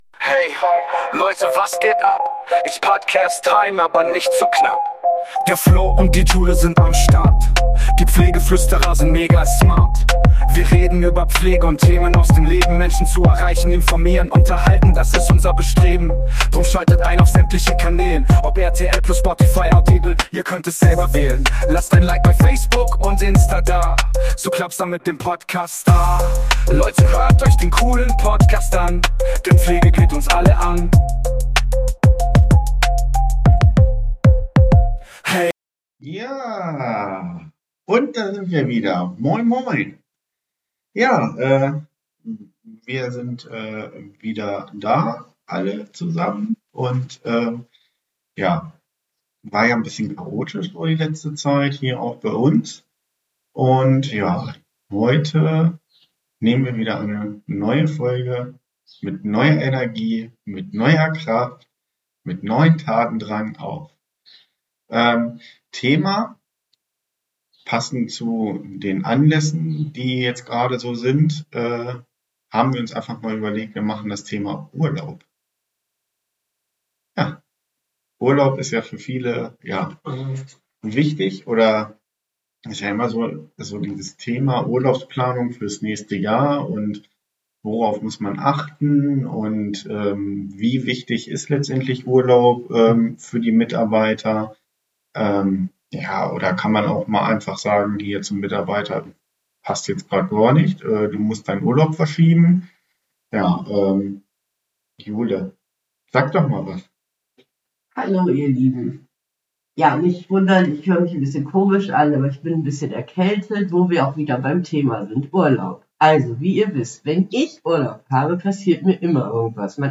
Das sind alles spannende Fragen die wir einfach mal bequatschen wollen und einfach ein Talk daraus gemacht haben wie man ihm im Alltag kennt und wo jeder seine Denkweise rein bringt.